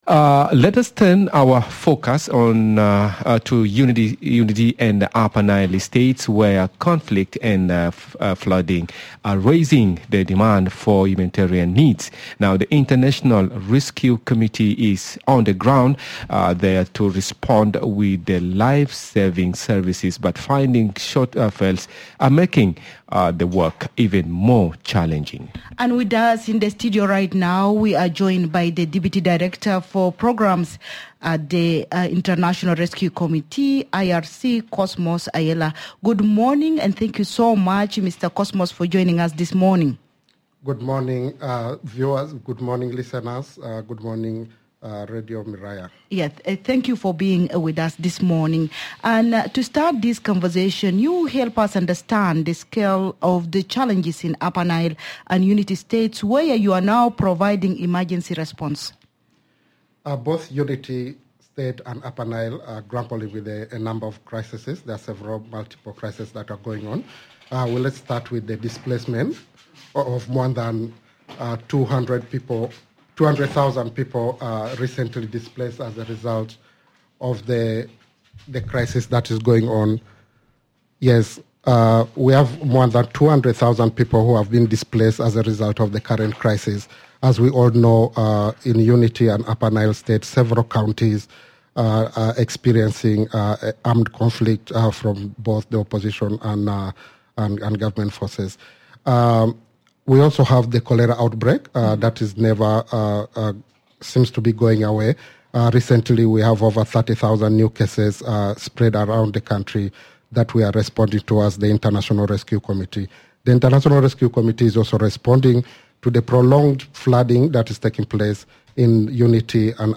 Radio Miraya